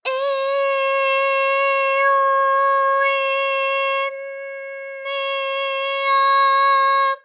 ALYS-DB-001-JPN - First Japanese UTAU vocal library of ALYS.
e_e_o_e_n_e_a.wav